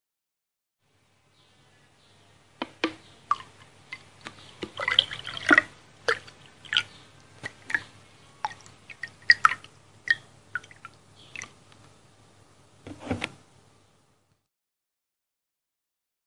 Metal Water Bottle Movement Filling and Emptying
描述：Handling a metal sports bottle, filling it with water and then emptying it again in a sink.
标签： fill filling metalbottle pouring water
声道立体声